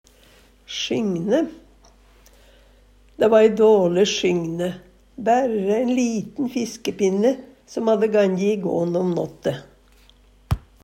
sjynje - Numedalsmål (en-US)